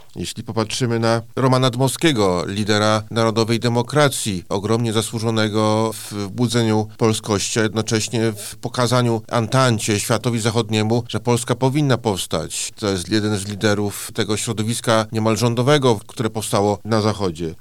O tym, z czego szczególnie zasłynął nasz bohater, mówi zastępca prezesa IPN dr Mateusz Szpytma: